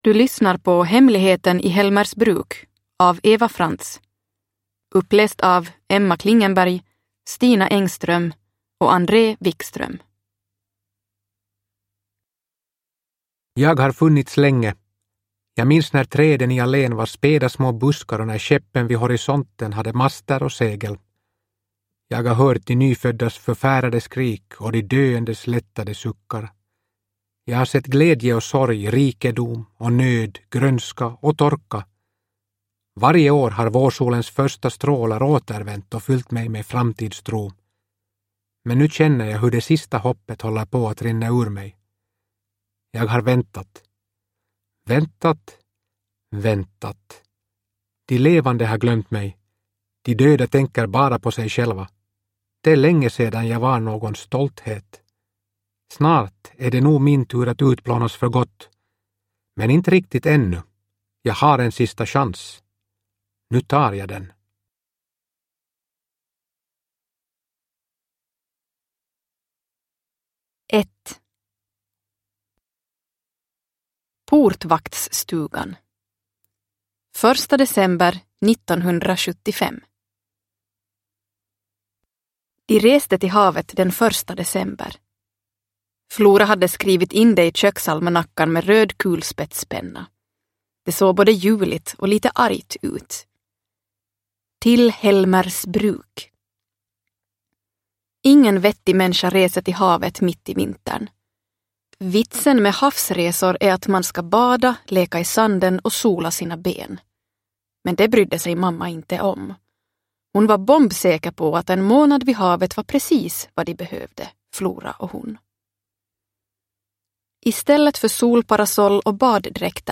Hemligheten i Helmersbruk – Ljudbok – Laddas ner